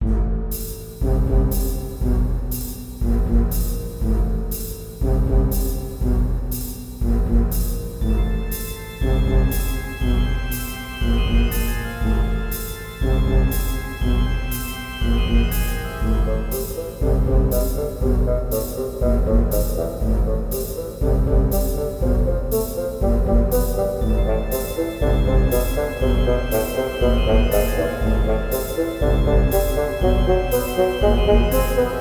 低音楽器たちの重い前奏にファゴットが軽やかな足取りで饒舌に語ります。